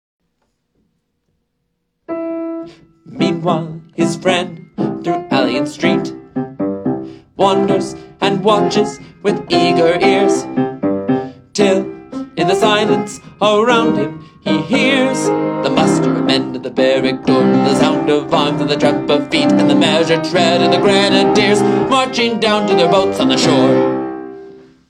Easy Piano and Voice